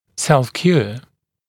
[ˌself’kjuə][ˌсэлф’кйуа]самоотверждаемый